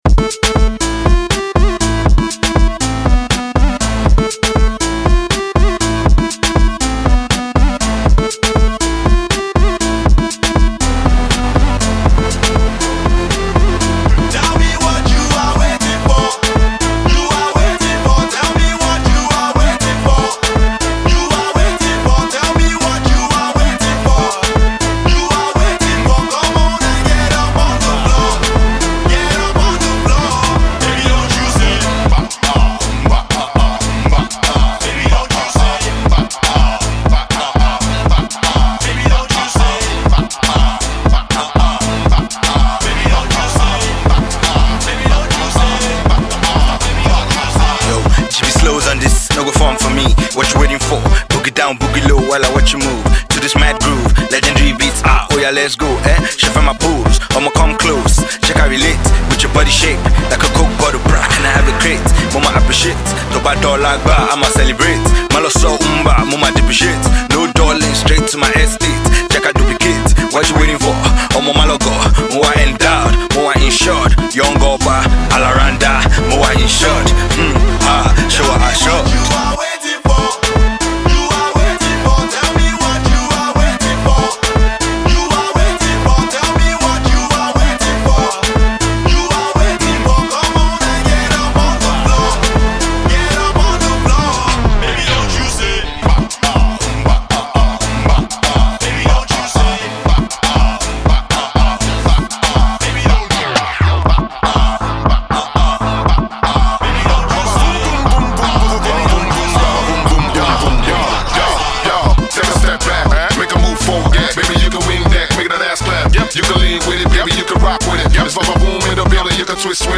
Afro-Dance track crafted for the dance floor